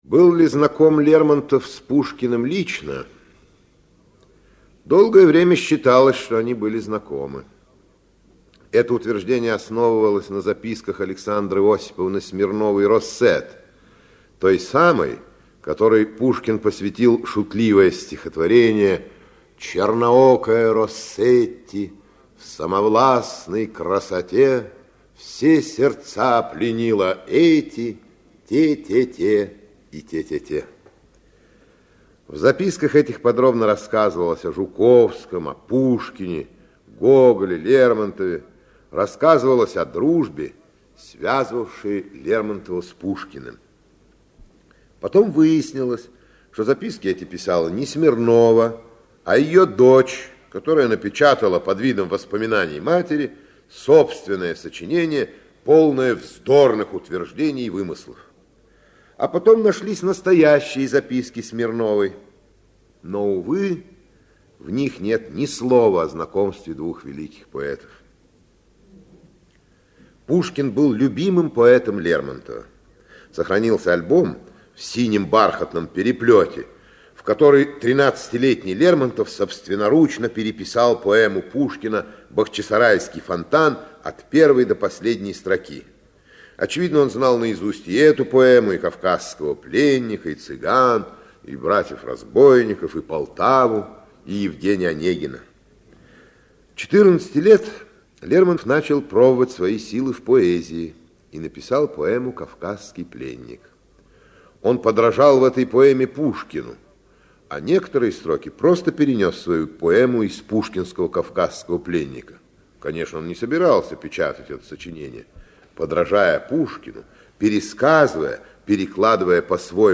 Аудиокнига Был ли знаком Лермонтов с Пушкиным?
Автор Ираклий Андроников Читает аудиокнигу Ираклий Андроников.